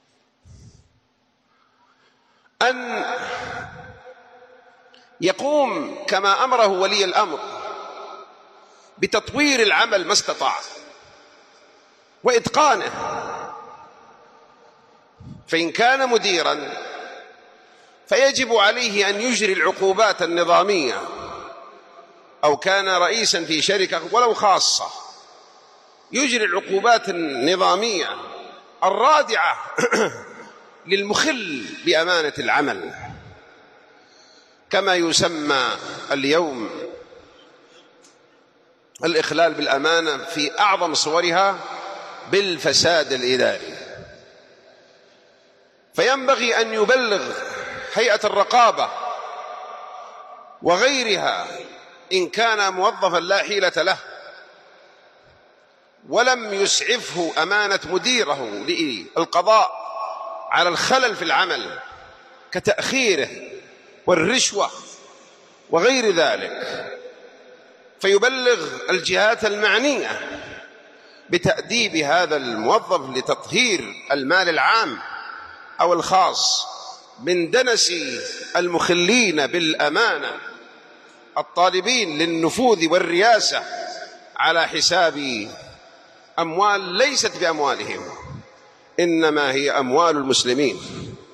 609 [ درر قحطانية ] - وجوب إجراء العقوبات النظامية الرادعة على المخالفين للأنظمة ولي الأمر وأمانة العمل والإبلاغ عن الفساد الإداري { خطبة الجمعة } .